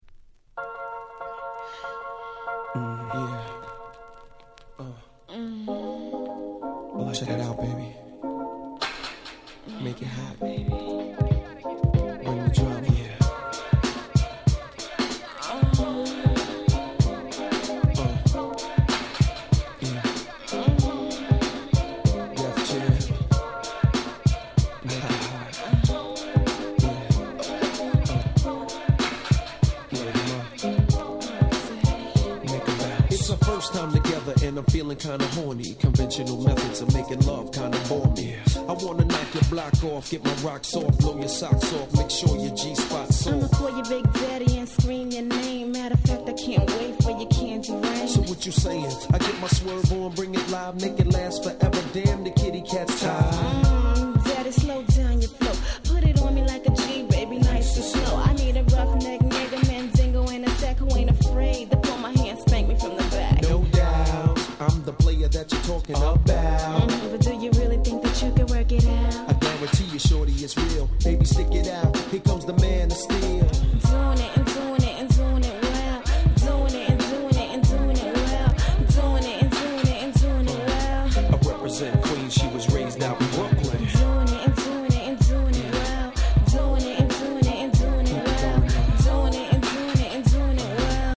90's 80's Boom Bap ブーンバップ Old School オールドスクール